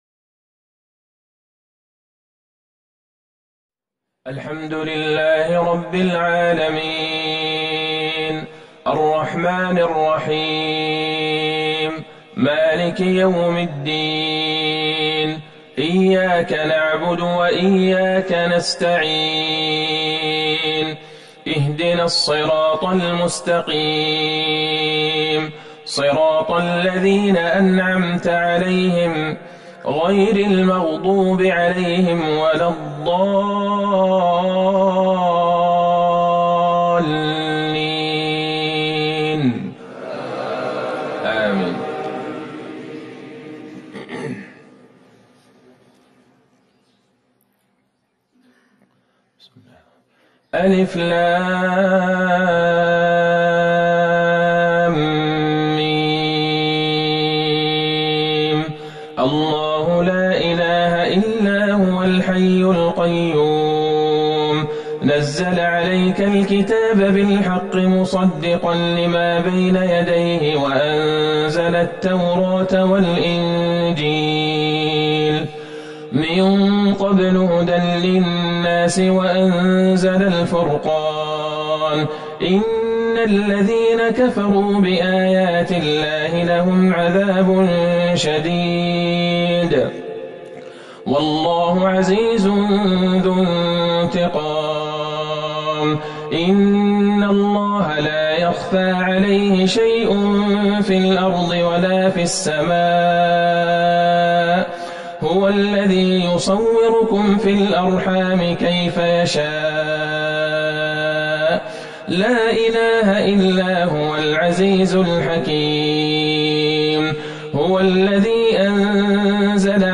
صلاة الفجر ١٥ جمادى الآخرة ١٤٤١هـ من سورة ال عمران | Fajr Prayer 9-2-2020 Surat Ah Al-Omran > 1441 🕌 > الفروض - تلاوات الحرمين